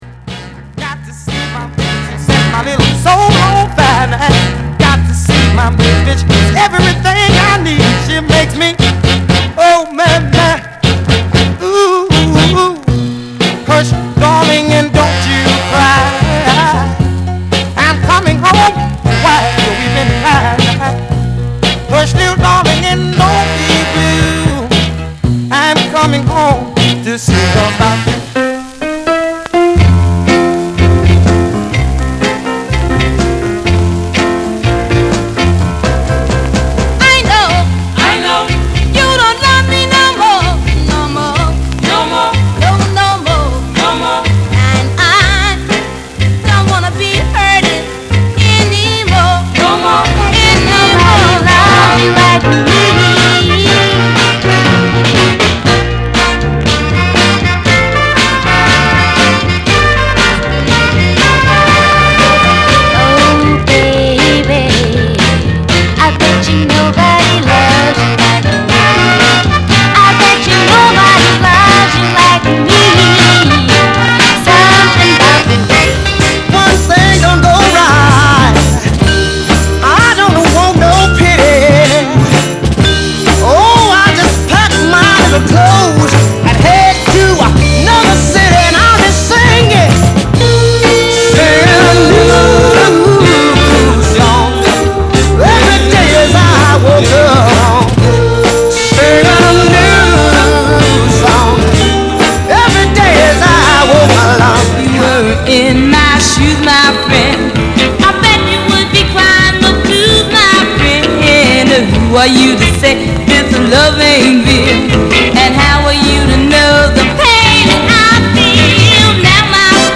両面やや傷あり再生良好です NEW ORLEANS CLASSIC HIT
/盤質/両面やや傷あり再生良好です/US PRESS